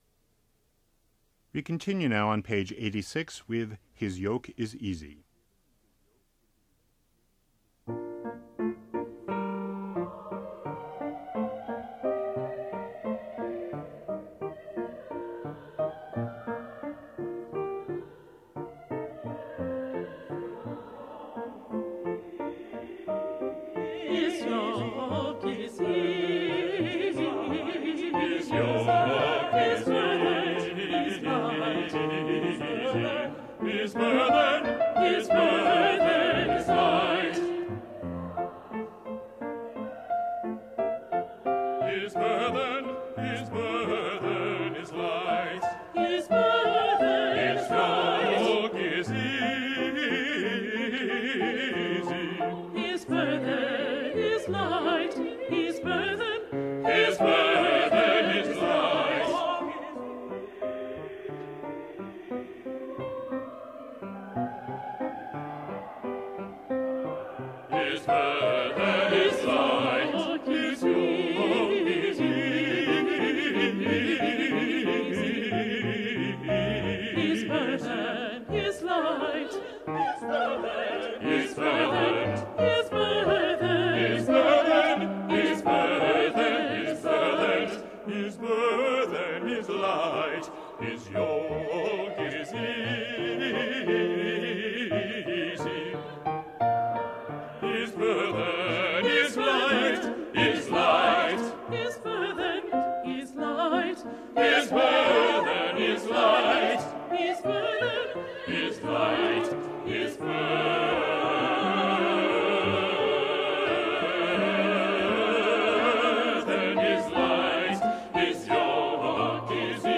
They are divided into two sections with either Alto and Bass together  or  Soprano and Tenor – since in each case the parts are well separated and tonally different you should be able to hear your part fairly clearly.
Alto/Bass